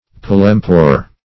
Search Result for " palempore" : The Collaborative International Dictionary of English v.0.48: Palempore \Pal`em*pore"\ (p[a^]l`[e^]m*p[=o]r"), n. A superior kind of dimity made in India, -- used for bed coverings.
palempore.mp3